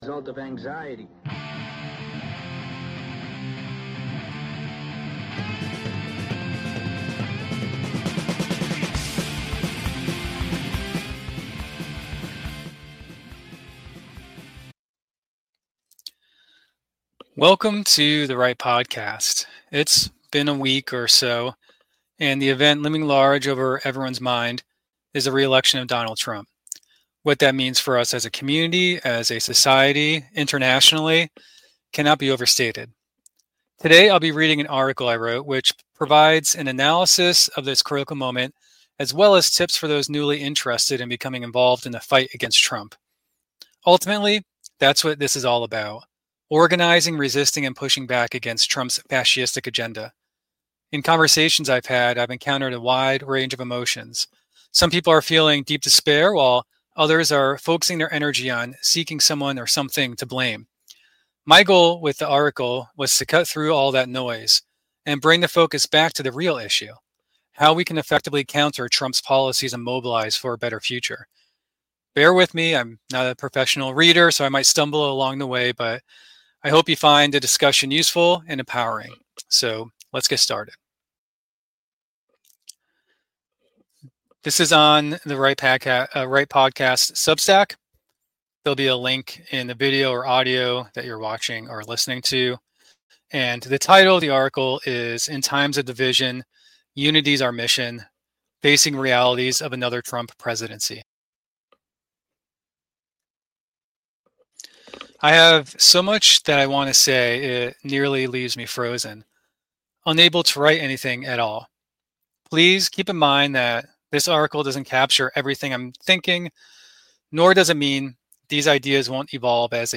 Join me as I read through "stumbling at times" an article I wrote on Substack about the election of Trump, highlighting key issues to watch and actionable steps for those newly inspired to resist a potential second Trump administration. The piece covers a range of topics, including inflation and the economy, Gen Z's role, the global rise of right-wing populism, the conflicts in Ukraine and Israel, strategies for meaningful conversations with Trump voters, fresh opportunities for activism, practical tips for new advocates, and more.